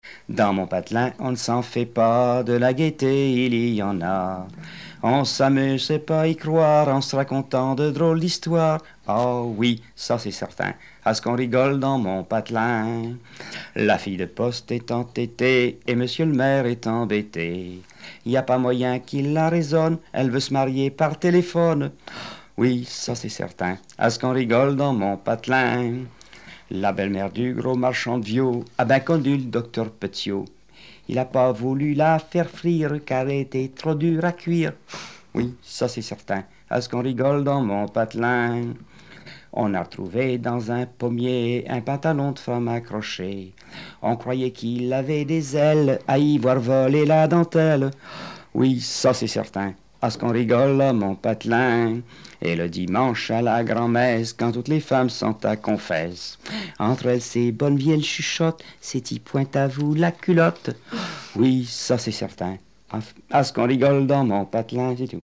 Témoignages et chansons populaires et traditionnelles
Pièce musicale inédite